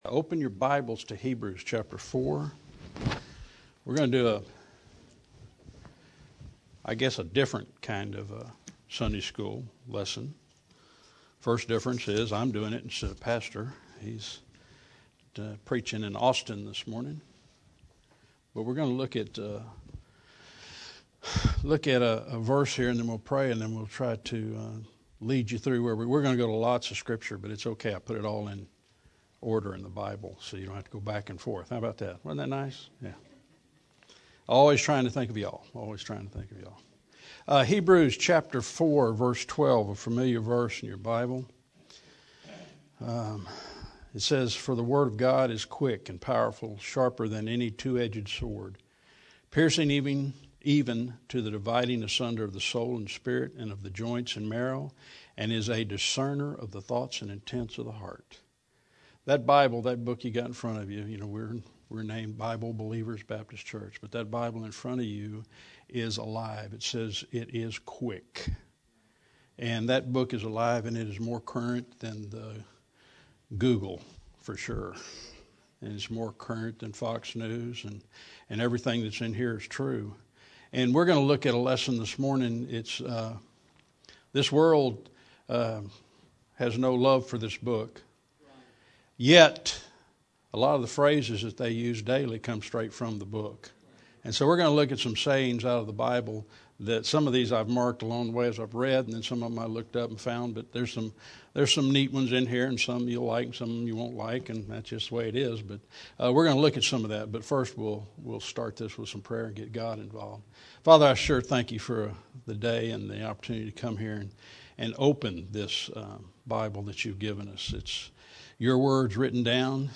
In this lesson we will look at as many as time permits and will in no way come close to exhausting all the references that exist.